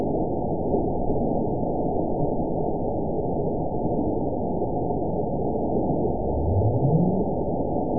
event 920304 date 03/14/24 time 22:14:22 GMT (1 year, 1 month ago) score 9.59 location TSS-AB02 detected by nrw target species NRW annotations +NRW Spectrogram: Frequency (kHz) vs. Time (s) audio not available .wav